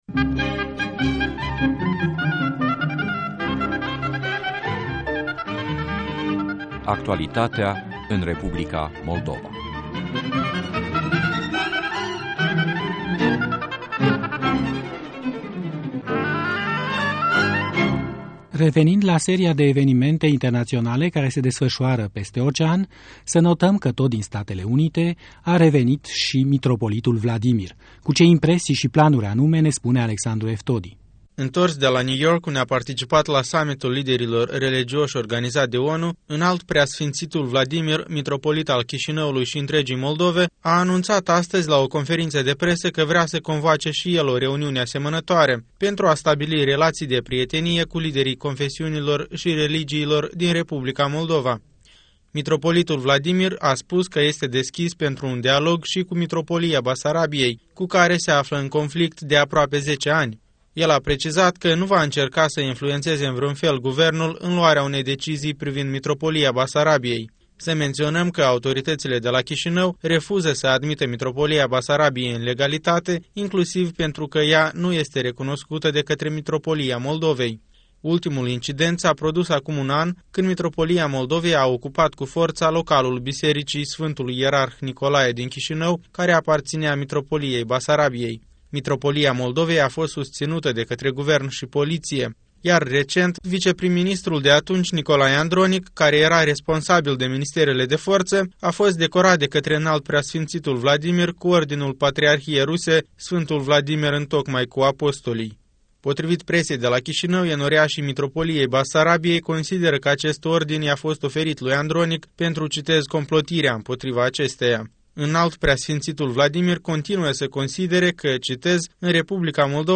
Conferința de presă a mitropolitului Vladimir